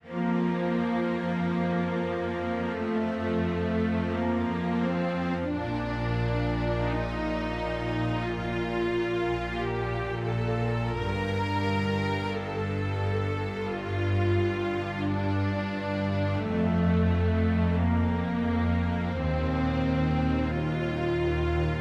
小提琴管弦乐2
描述：小提琴管弦乐2 88 BPM
Tag: 88 bpm Classical Loops Strings Loops 3.67 MB wav Key : Unknown